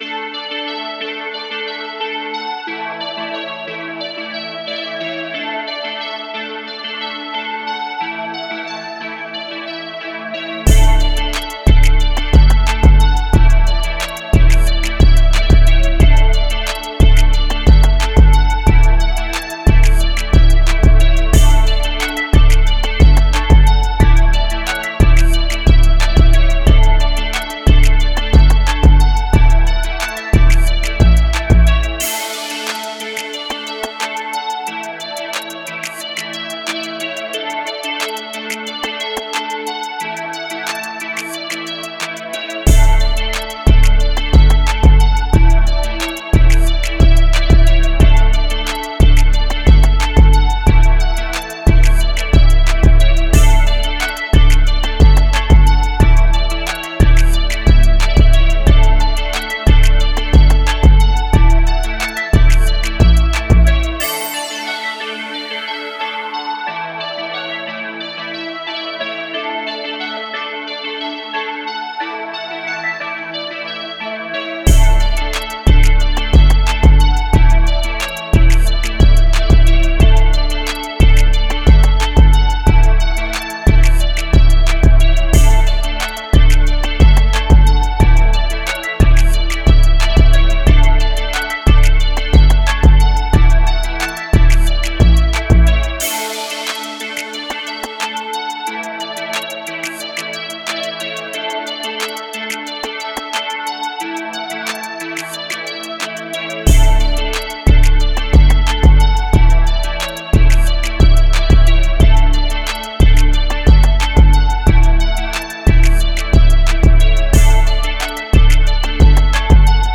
Key:Amajor